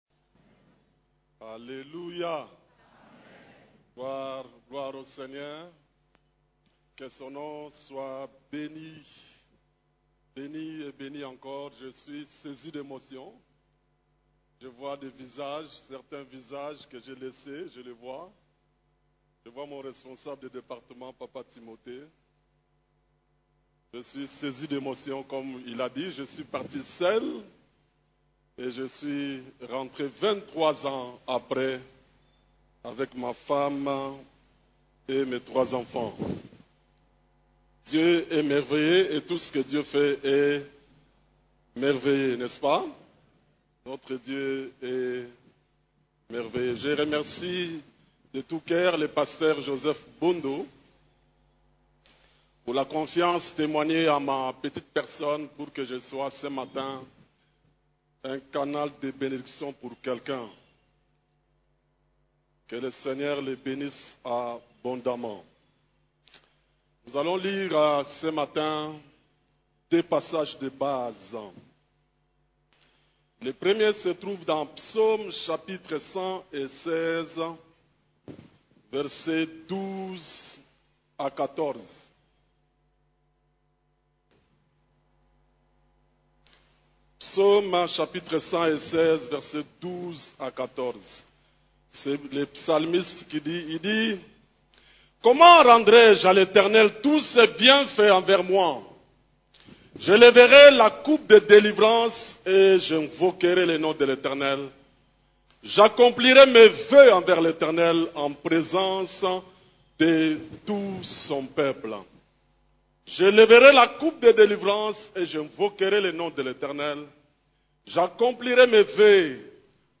CEF la Borne, Culte du Dimanche, Les actions de grâce